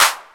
Run Clap.wav